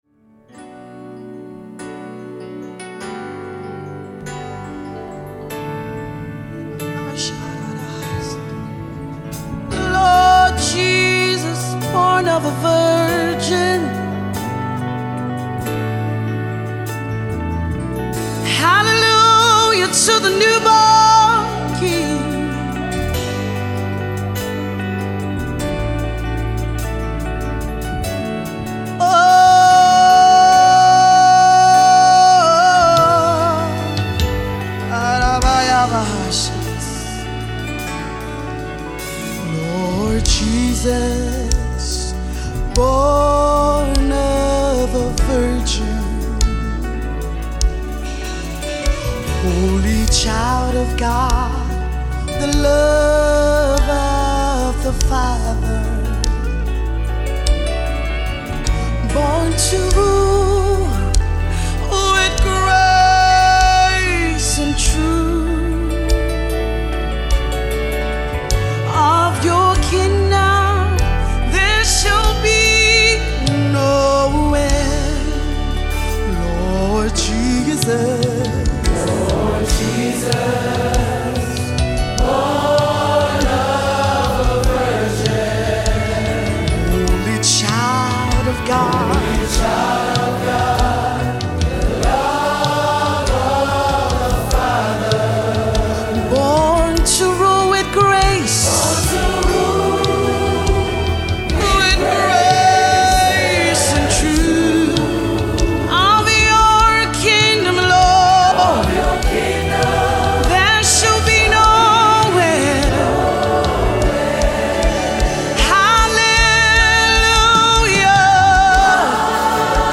Christmas Songs